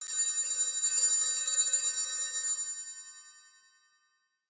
• Качество: 129, Stereo